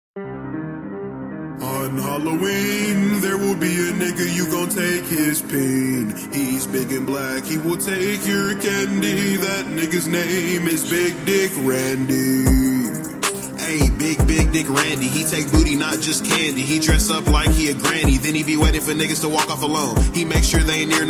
Hehe